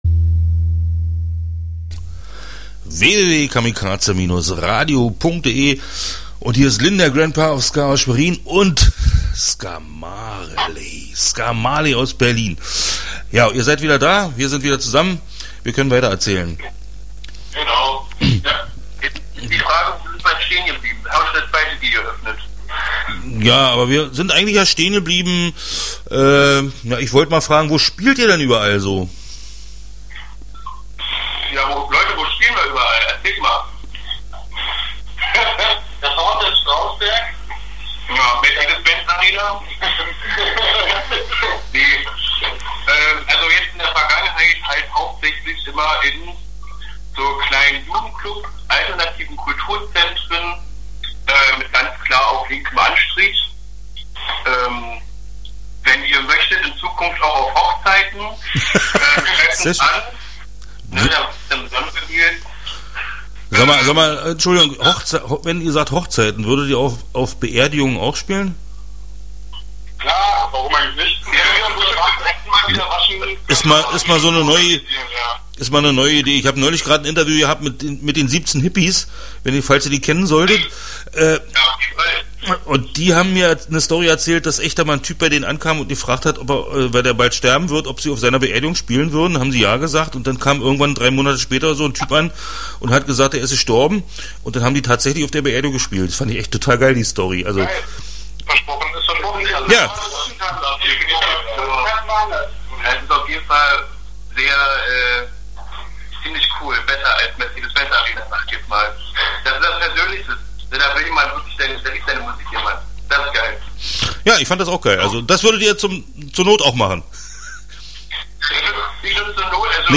2019-02-25. Interview 01 (10:17)
Bis auf einen war die ganze Band dabei!